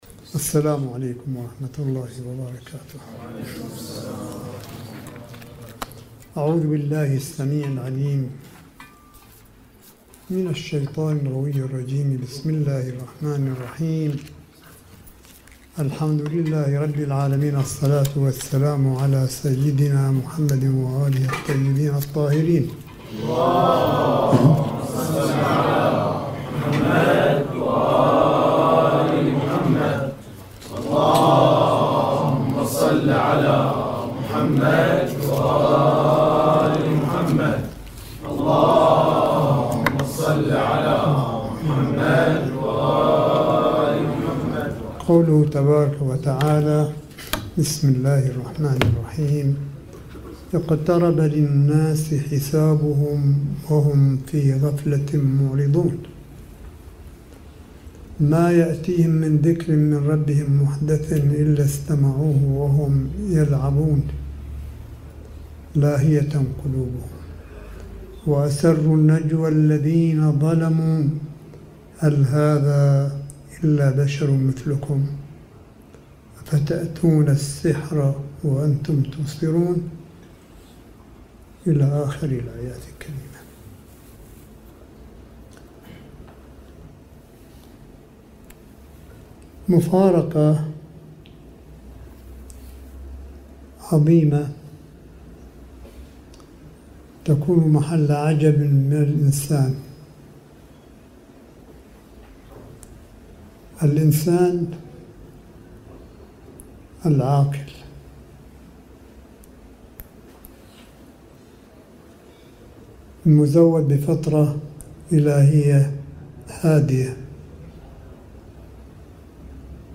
ملف صوتي للحديث القرآني لسماحة آية الله الشيخ عيسى أحمد قاسم حفظه الله بقم المقدسة – 4 شهر رمضان 1440 هـ / 10 مايو 2019م